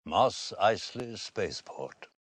Mos Eisley (pronounced /mɑs aɪzliː/, moss izelee, (